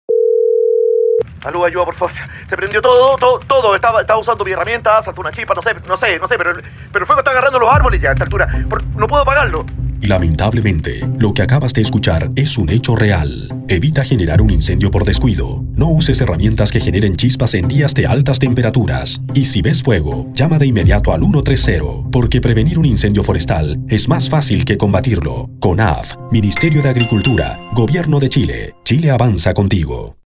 Frases radiales